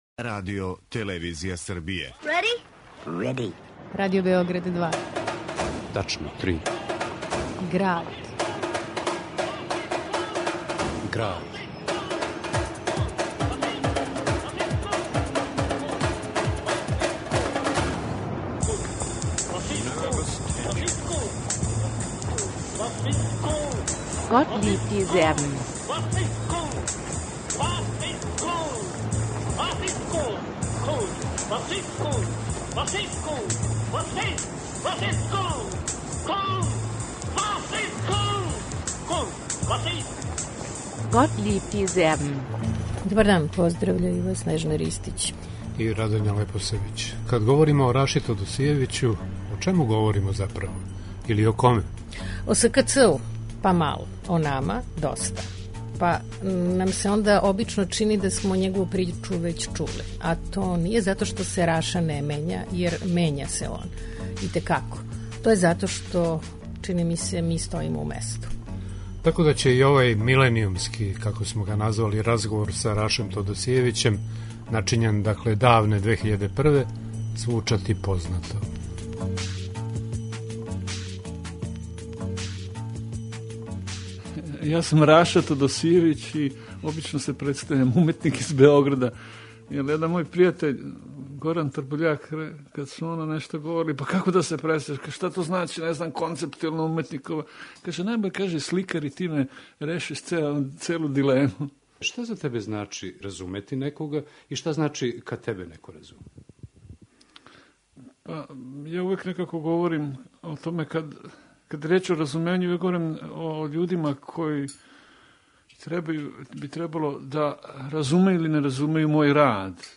У Граду , Раша Тодосијевић на снимку из давне 2001. говори о концептуалној уметности, школовању, својим радовима, нама...